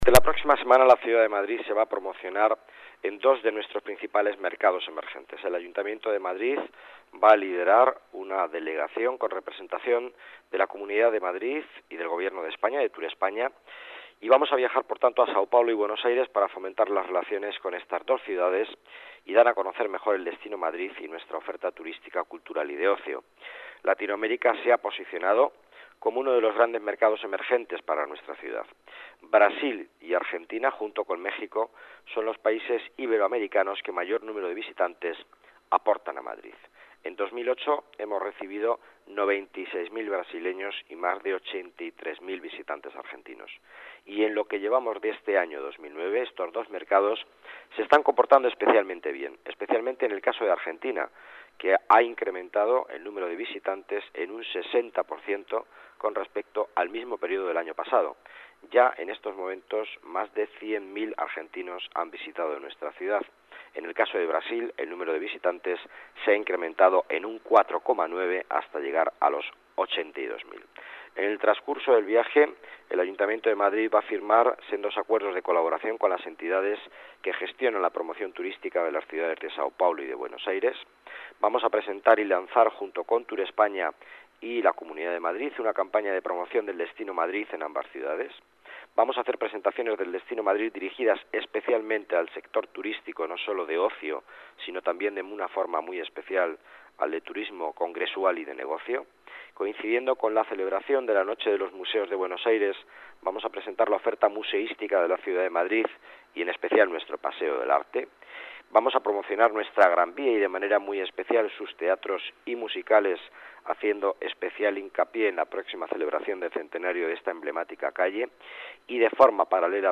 Nueva ventana:Miguel Ángel Villanueva, delegado de Economía, avanza el contenido del viaje